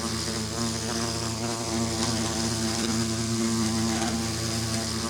1.21.5 / assets / minecraft / sounds / mob / bee / loop3.ogg